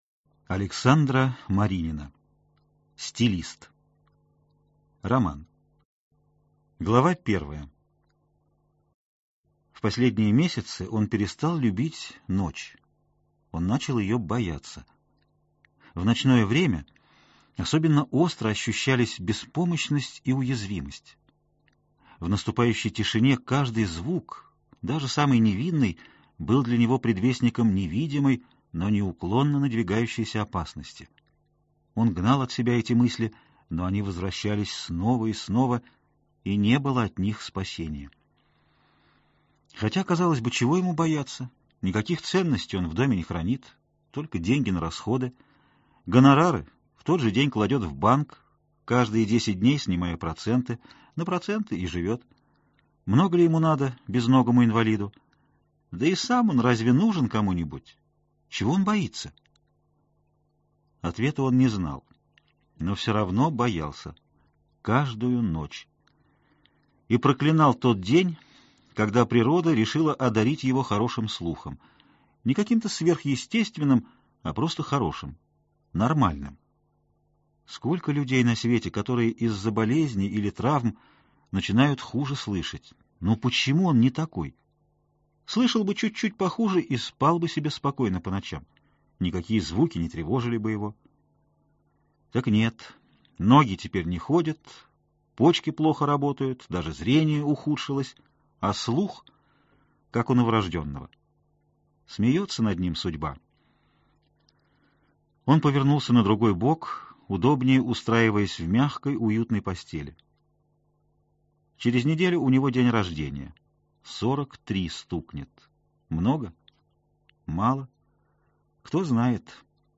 Аудиокнига Стилист. Часть 1 | Библиотека аудиокниг